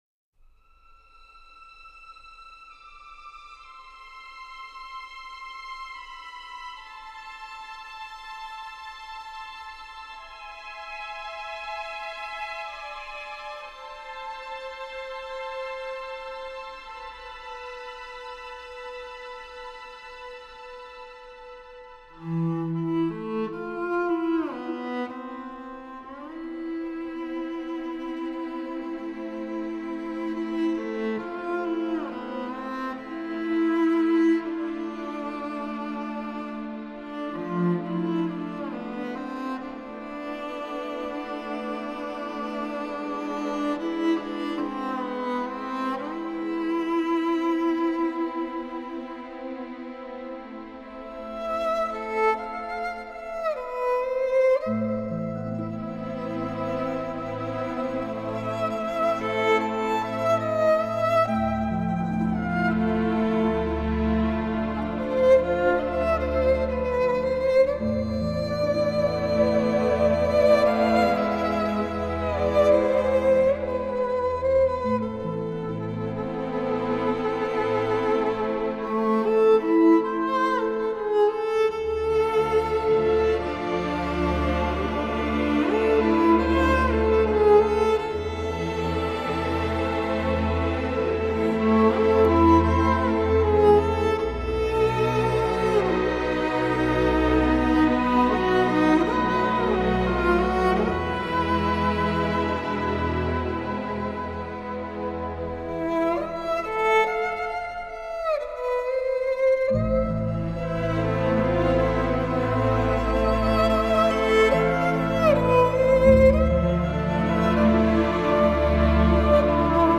低沉耐人寻味专辑延续了小提琴和钢琴在乐曲中的主导地位和对古朴叙事歌谣的表现力
融合了爱尔兰空灵飘渺的乐风，挪威民族音乐及古典音乐